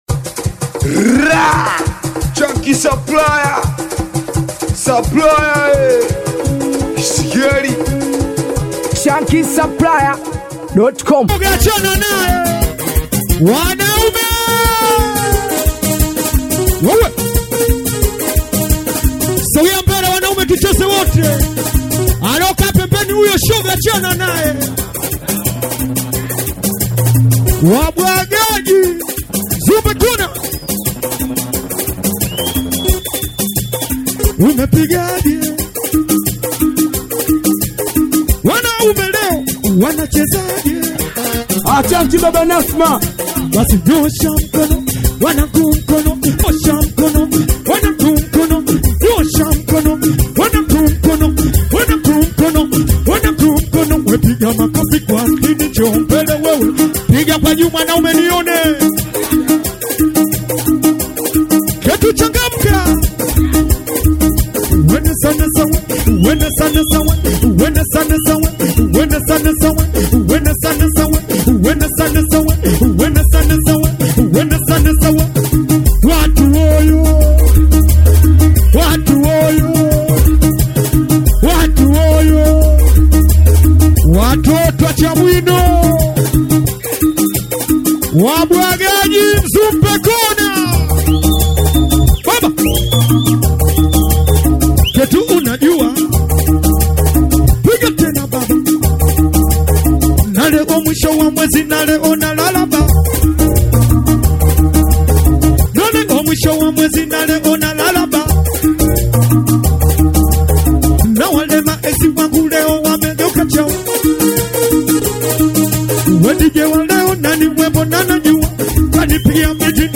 SHOW LIVE ZA KIBABE KITOKA MOROGORO